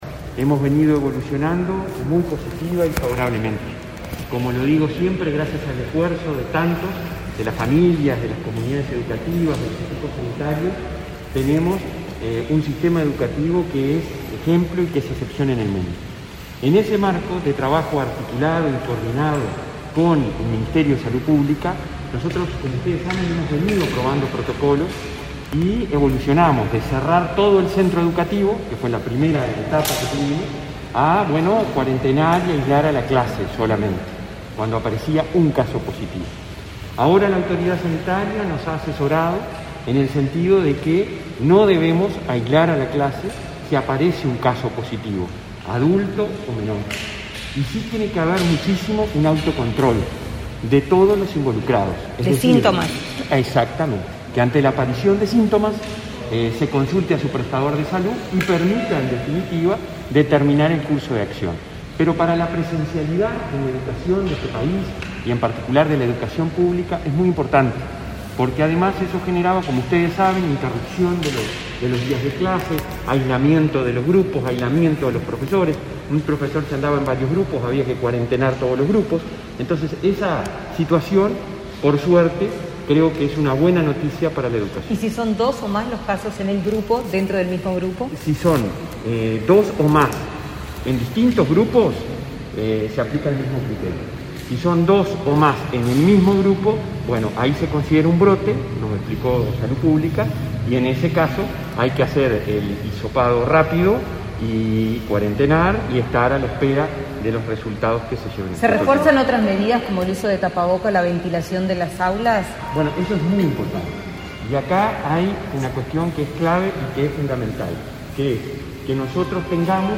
Declaraciones del presidente de la ANEP, Robert Silva, a la prensa
Sobre el tema, el presidente de la ANEP efectuó, ese 27 de enero, declaraciones a la prensa.